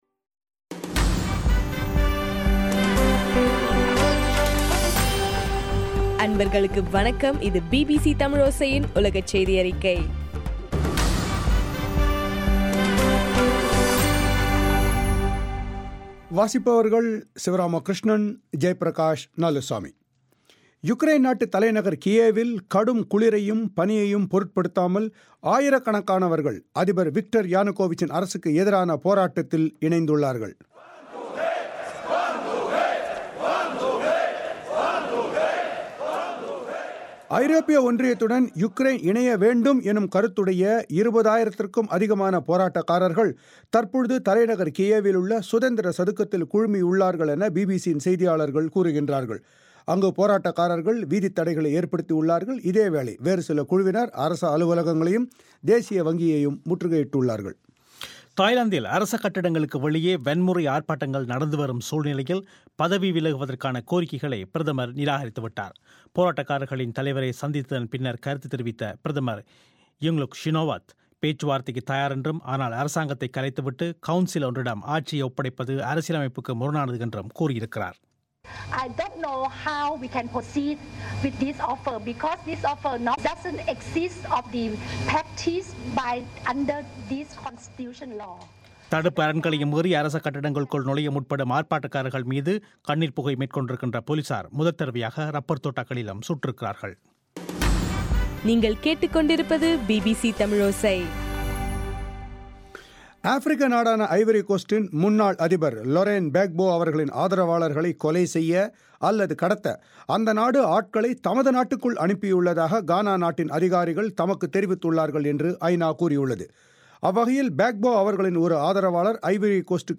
டிசம்பர் 2 பிபிசி தமிழோசை உலகச் செய்தி அறிக்கை